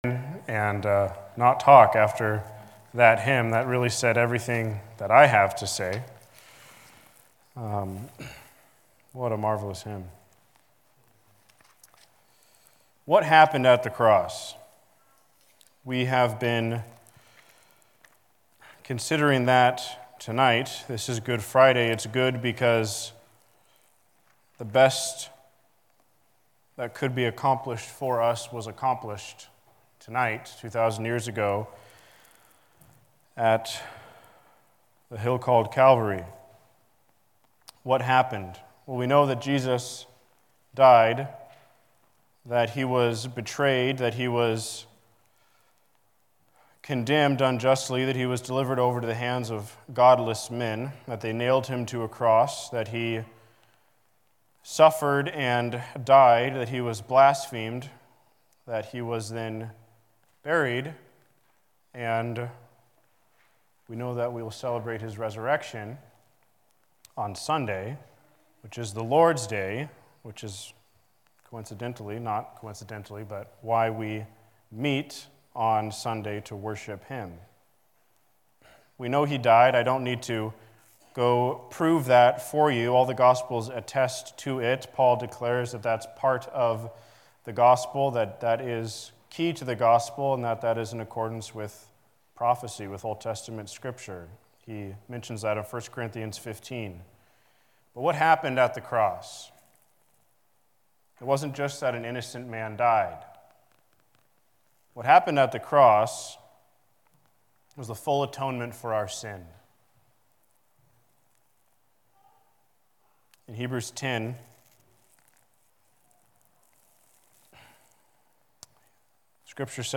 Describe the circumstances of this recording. Service Type: Holiday Service